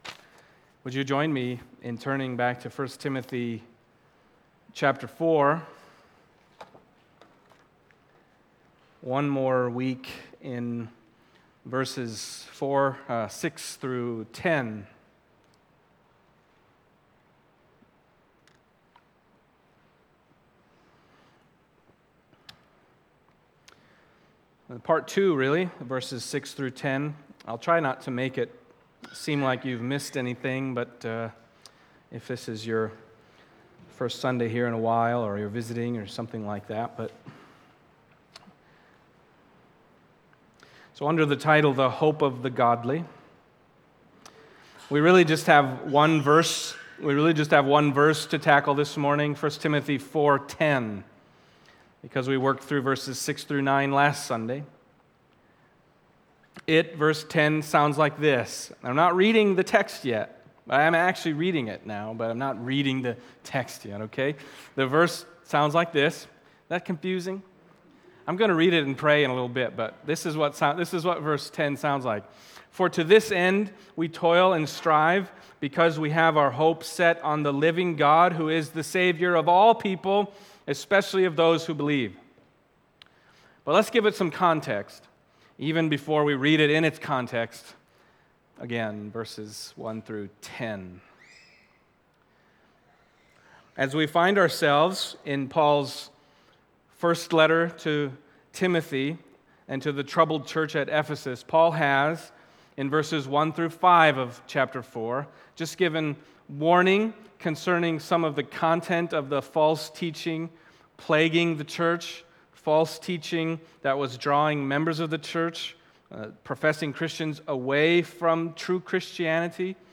1 Timothy Passage: 1 Timothy 4:6-10 Service Type: Sunday Morning 1 Timothy 4:6-10